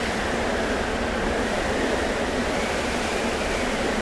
target_wind_fly_loop.ogg